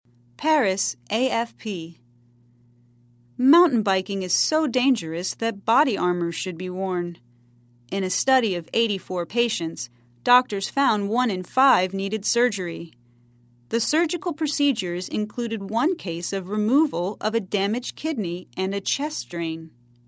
在线英语听力室赖世雄英语新闻听力通 第81期:骑登山车很危险的听力文件下载,本栏目网络全球各类趣味新闻，并为大家提供原声朗读与对应双语字幕，篇幅虽然精短，词汇量却足够丰富，是各层次英语学习者学习实用听力、口语的精品资源。